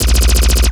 LASRGun_Alien Handgun Burst_03.wav